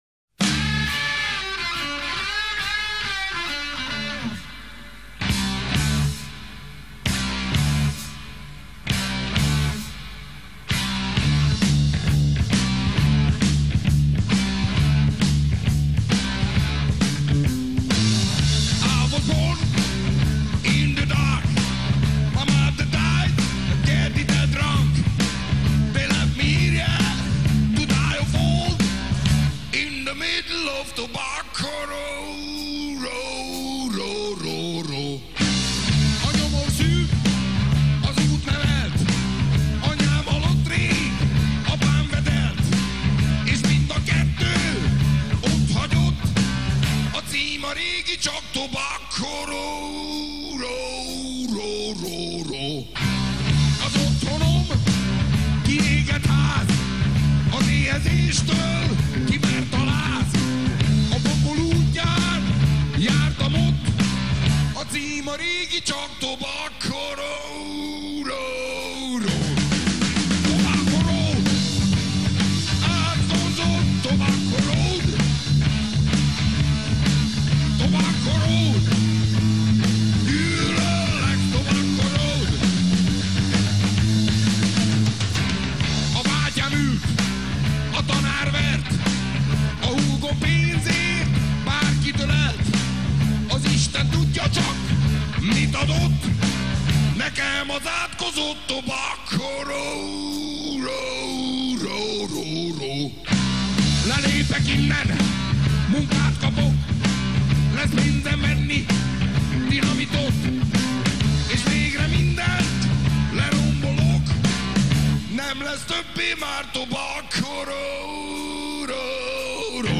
Качеством записей похвастаться не могу.